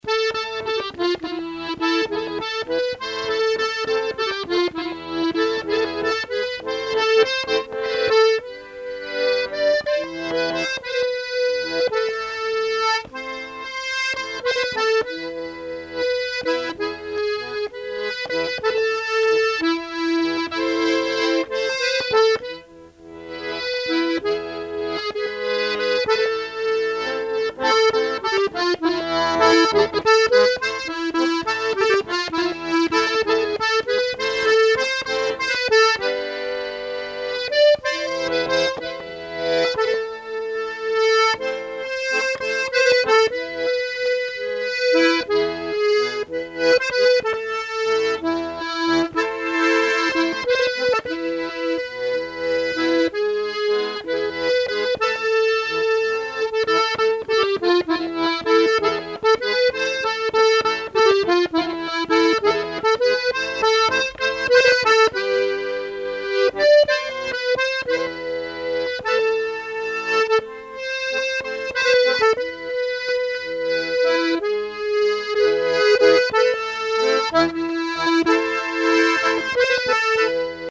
Genre laisse
Catégorie Pièce musicale inédite